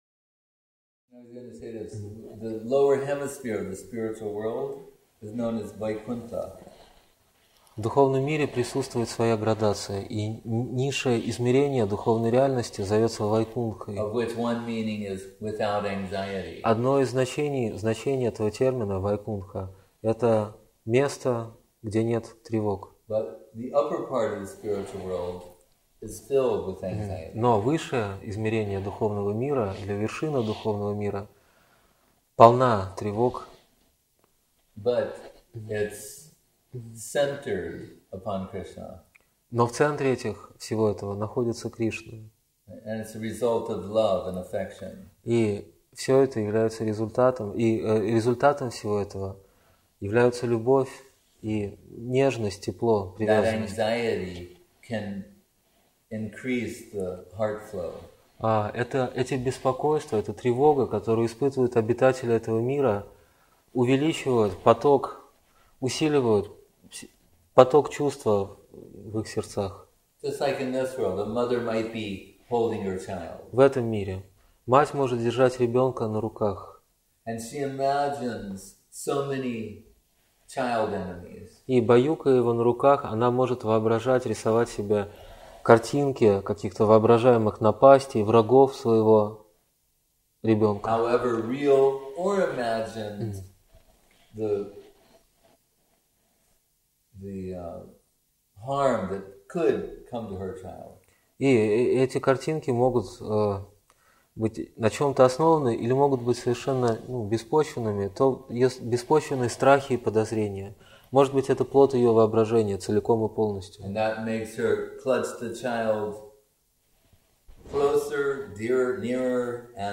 Место: Культурный центр «Шри Чайтанья Сарасвати» Москва